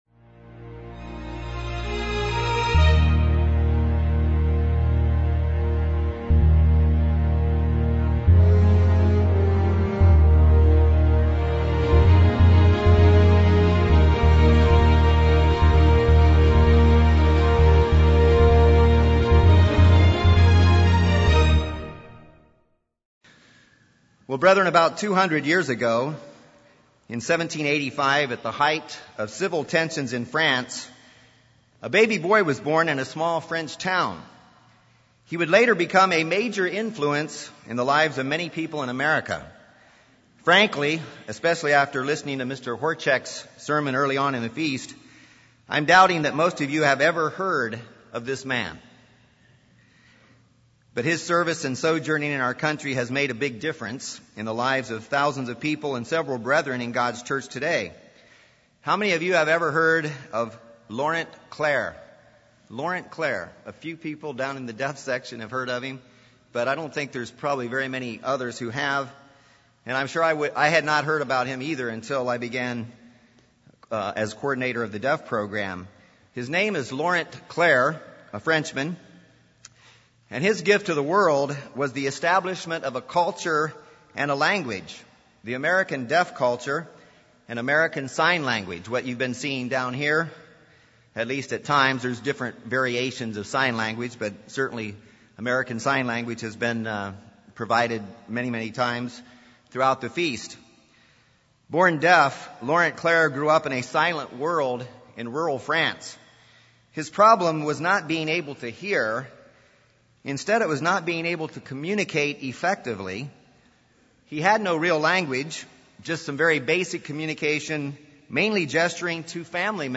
Like Laurent Clerc who left his native land and became a sojourner in a foreign land, we have been called by God to sojourn now in these temporary fleshly bodies, these tabernacles to be Christ's ambassadors. We've been called for a purpose to leave our native land of Satan's society and uplift others with God's teachings and encourage them with our example of Christ living in us (2010 Feast of Tabernacles sermon from Panama City Beach, Florida).